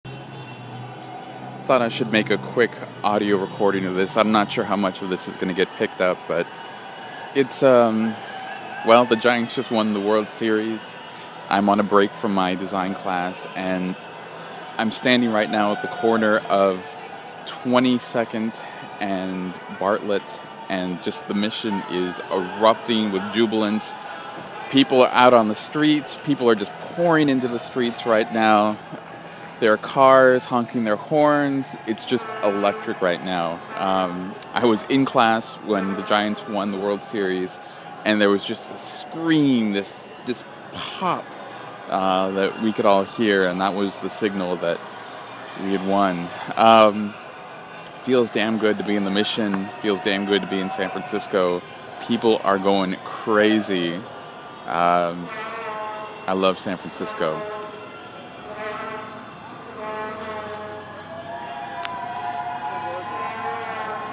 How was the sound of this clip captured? When I got outside moments later I made a quick field recording of the beautiful cacophony that came in from every direction and reported on what was going on at that exact moment.